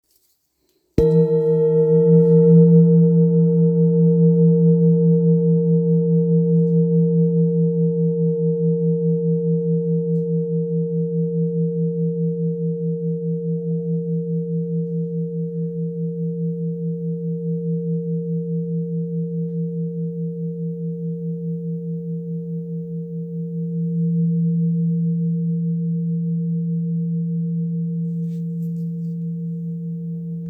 Kopre Singing Bowl, Buddhist Hand Beaten, Antique Finishing
Material Seven Bronze Metal
It is accessible both in high tone and low tone .
In any case, it is likewise famous for enduring sounds.